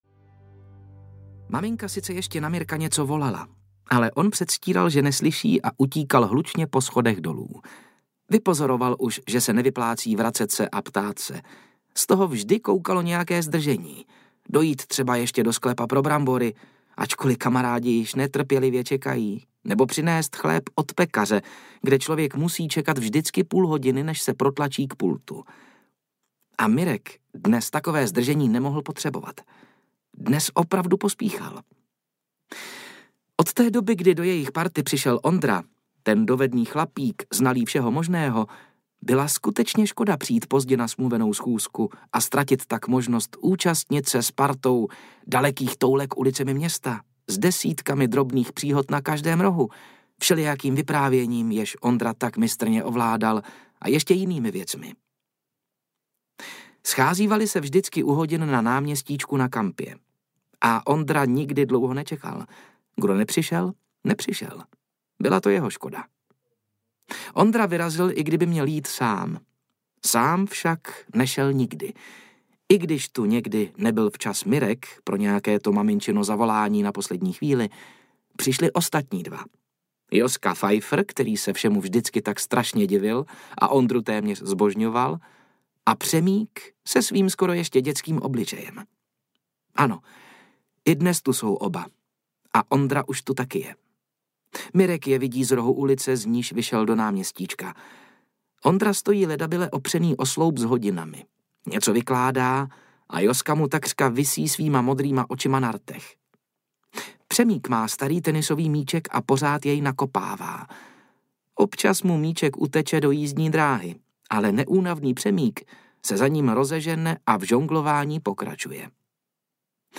Pod junáckou vlajkou audiokniha
Ukázka z knihy